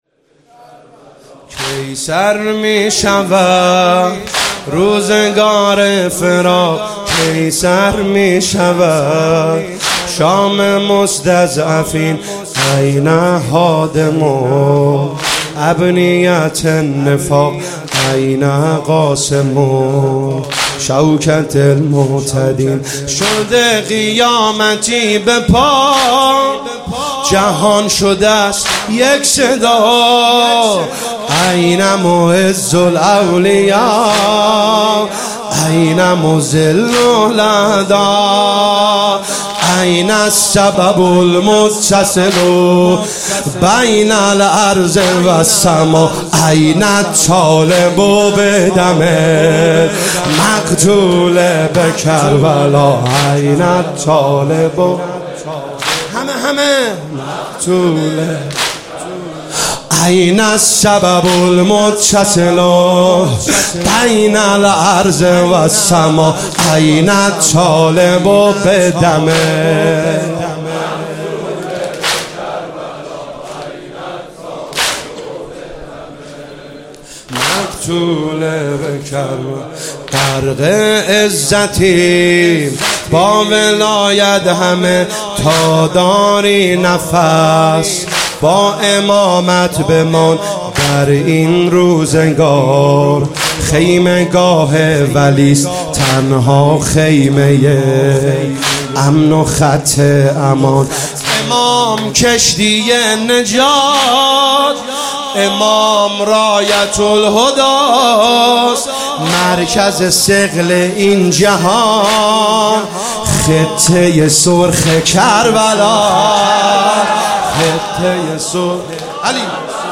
شب بیست ویکم رمضان1401